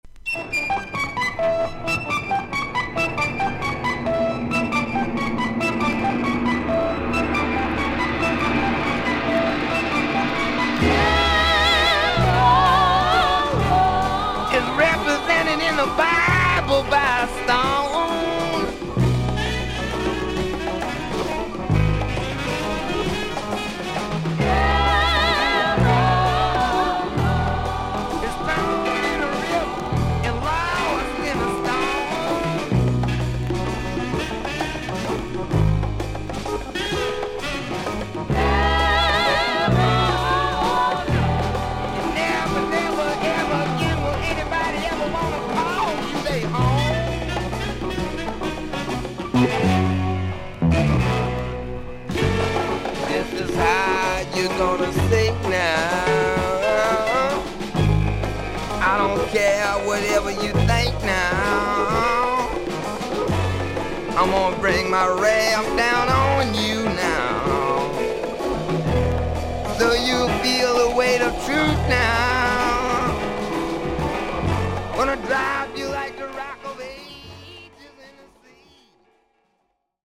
VG++〜VG+ 少々軽いパチノイズの箇所あり。クリアな音です。
ロック/R&Bピアニスト。 2作目のスタジオ・アルバム。